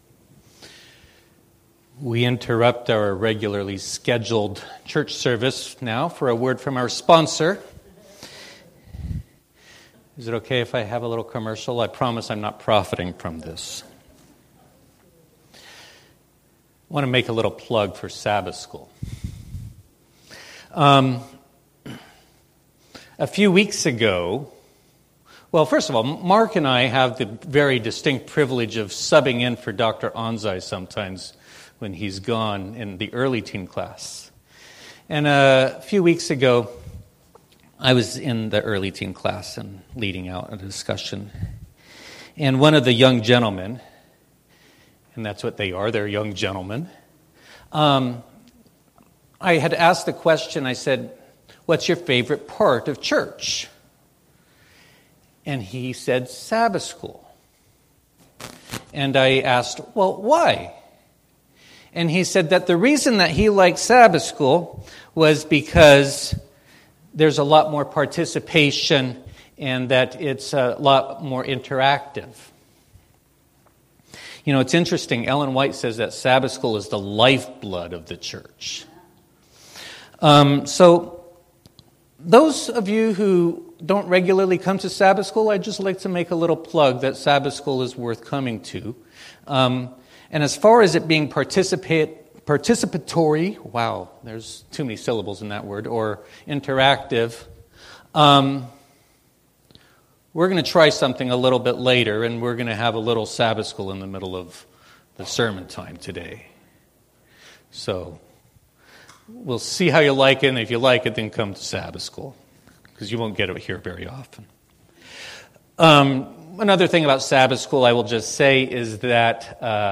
Service Type: Worship Service Topics: fear , God's Character